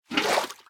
assets / minecraft / sounds / item / bucket / fill3.ogg